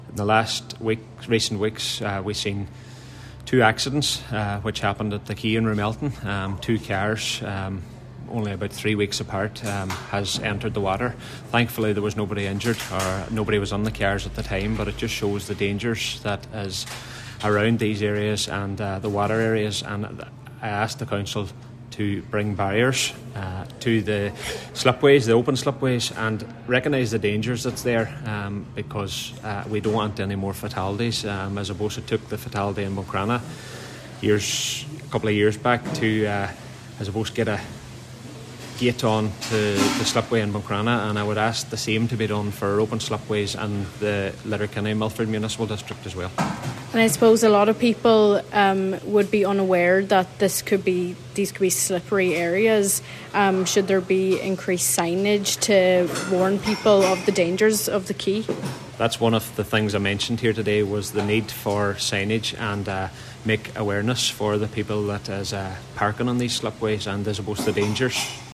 Cllr McGarvey says that it should not take a fatality to see action: